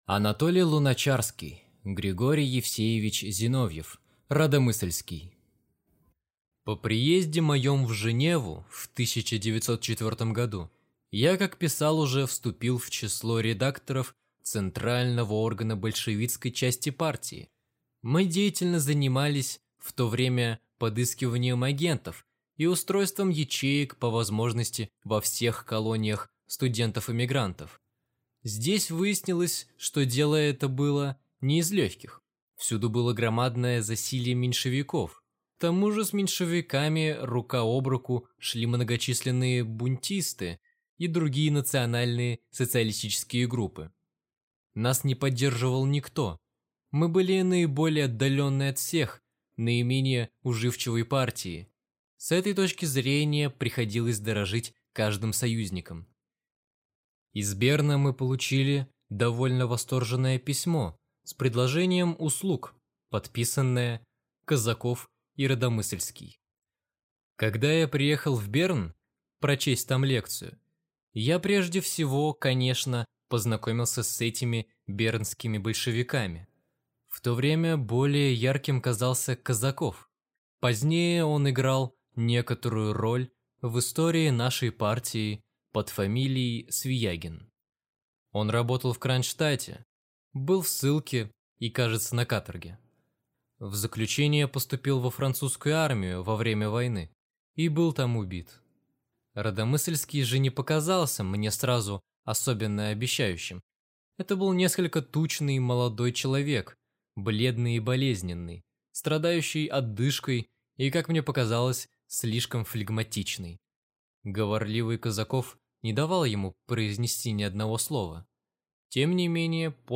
Аудиокнига Григорий Евсеевич Зиновьев (Радомысльский) | Библиотека аудиокниг